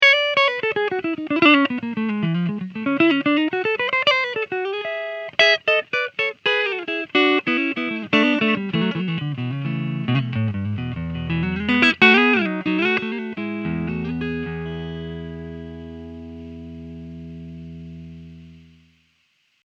Clean riff 1